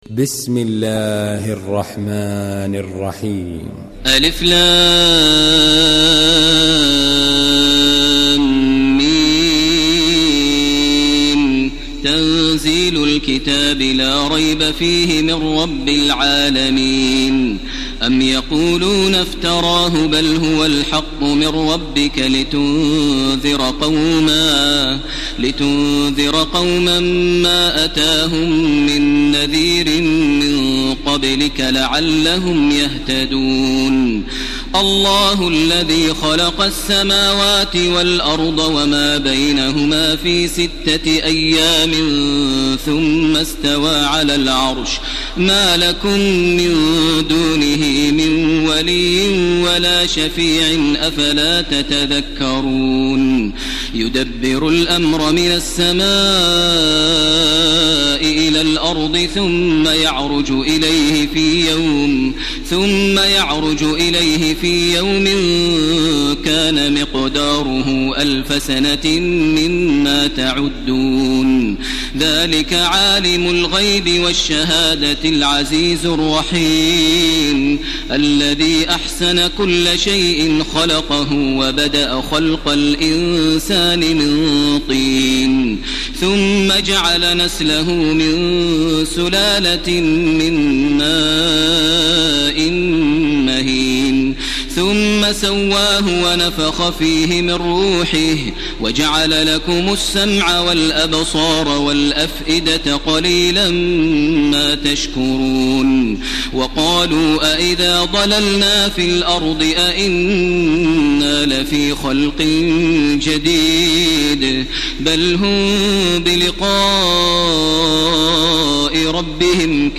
Surah As-Sajdah MP3 by Makkah Taraweeh 1431 in Hafs An Asim narration.